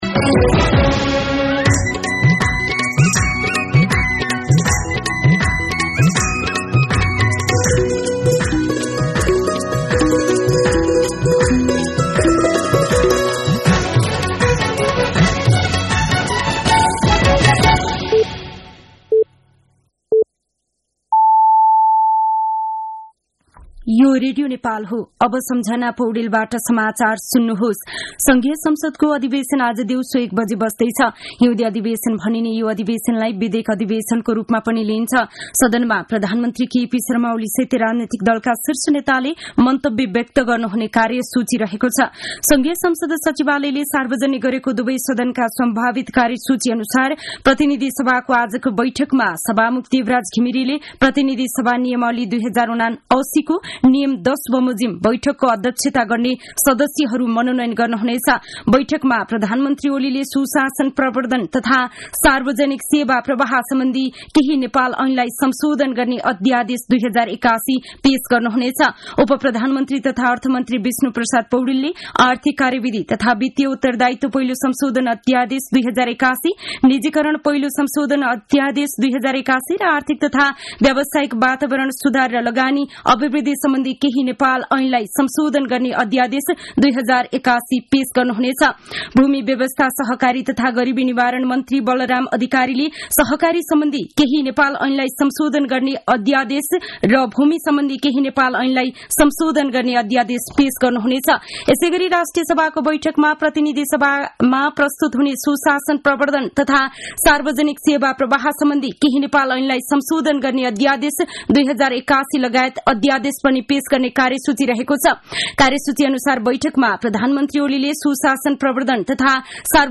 मध्यान्ह १२ बजेको नेपाली समाचार : १९ माघ , २०८१
12-pm-Nepali-News-2.mp3